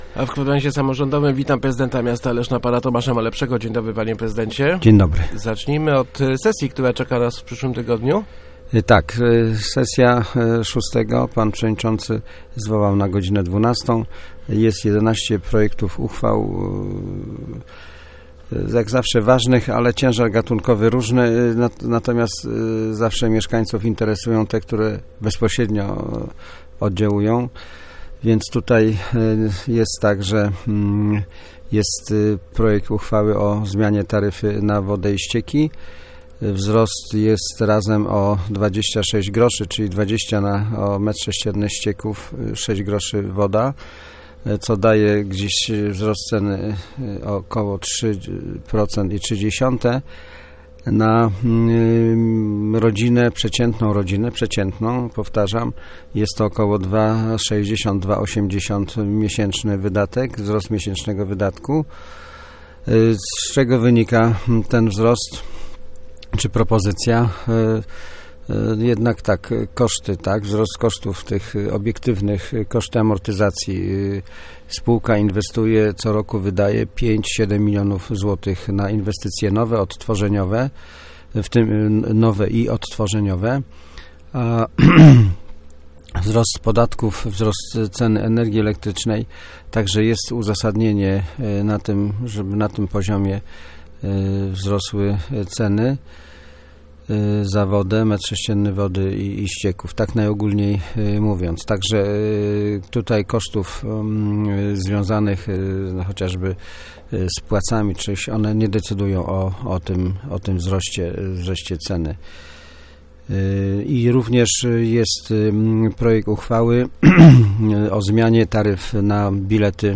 Gościem Kwadransa był prezydent Tomasz Malepszy.